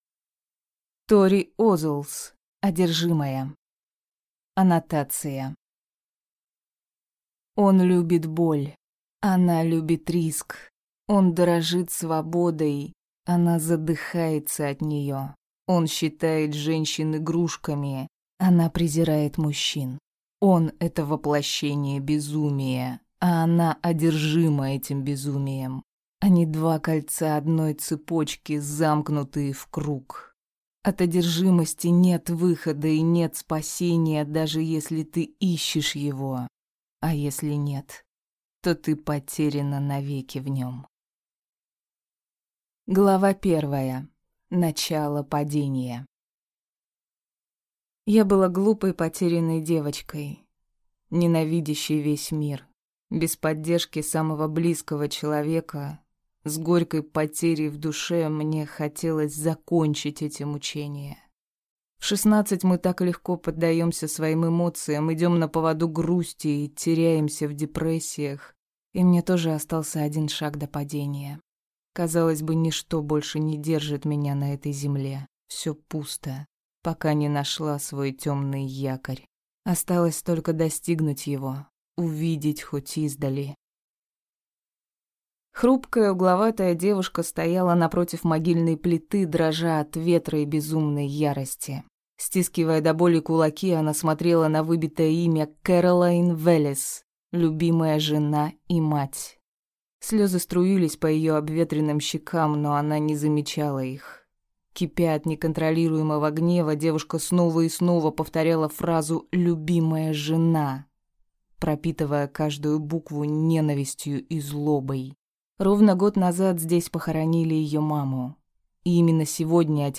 Аудиокнига Одержимая - купить, скачать и слушать онлайн | КнигоПоиск